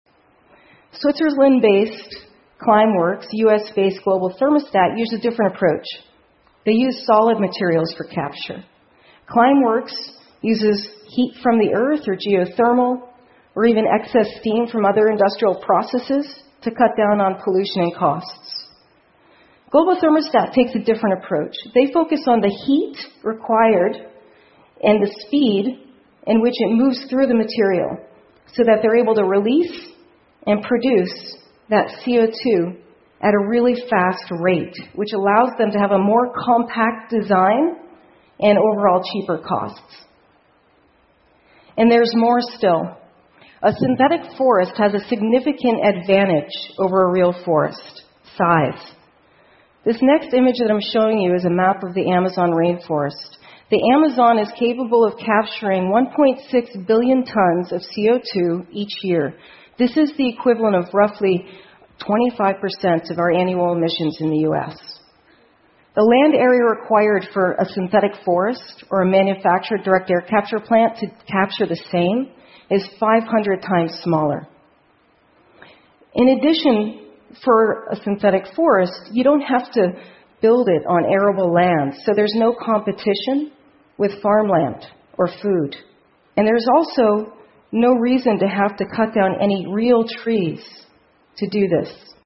TED演讲:从大气中移除二氧化碳的新方法() 听力文件下载—在线英语听力室